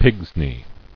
[pigs·ney]